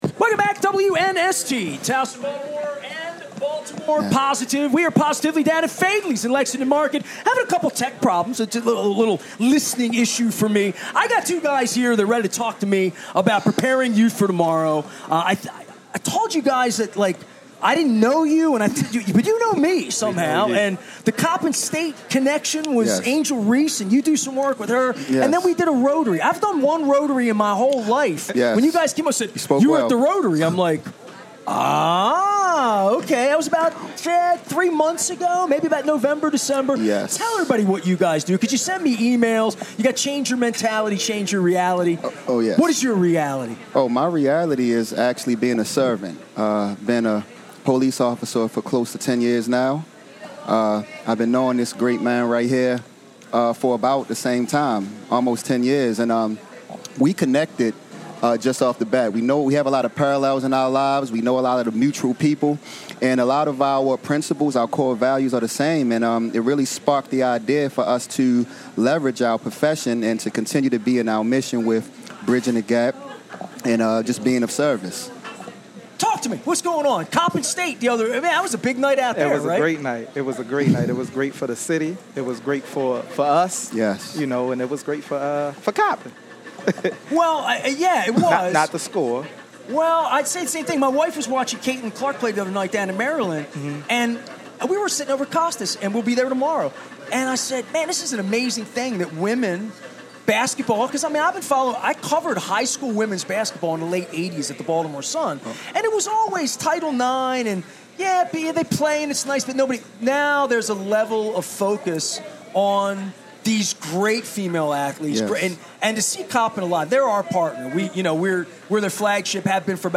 Crab Cake Row: Two young Baltimore police officers educate us on Preparing Youth For Tomorrow work